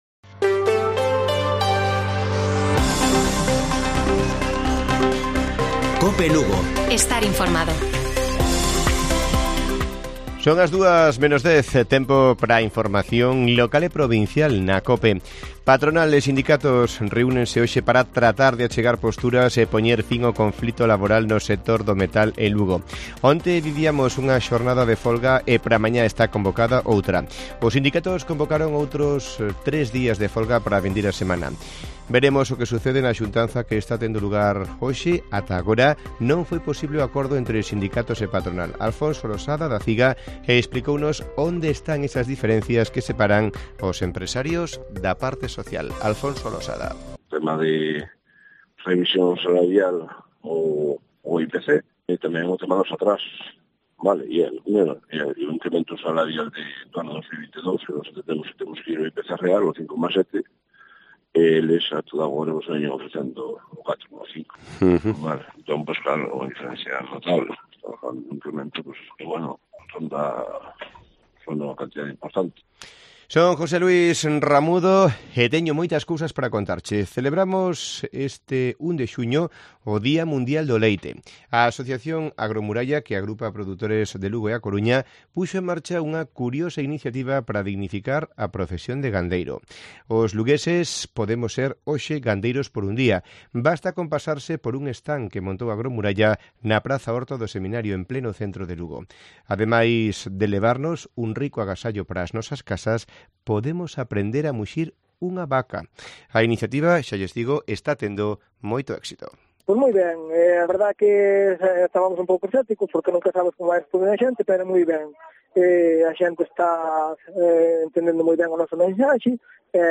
Informativo Mediodía de Cope Lugo. 01 de junio. 13:50 horas